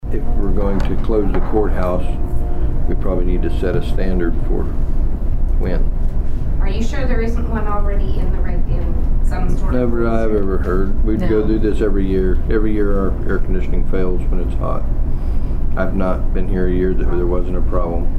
The Nowata County Commissioners met on Monday morning at the Nowata County Courthouse Annex.
Commissioner Troy Friddle talked about the air conditioning in the courthouse.